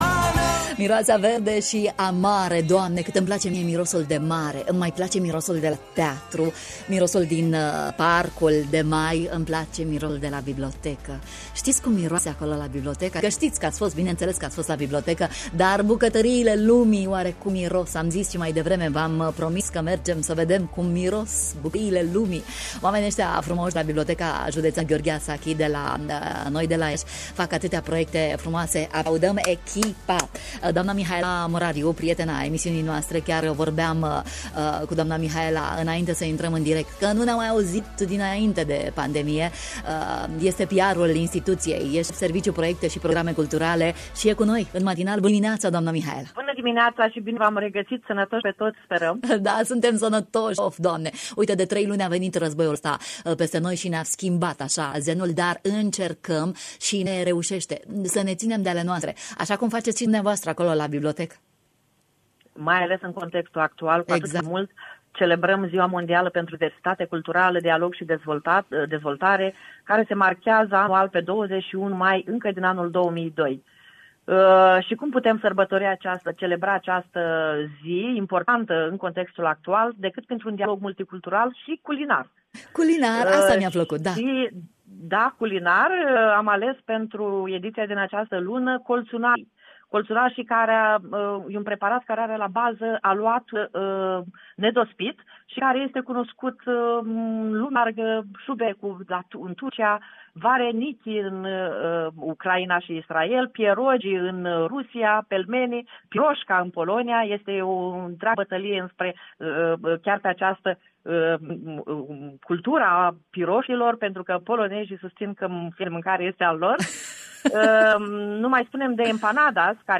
în direct la Radio România Iaşi: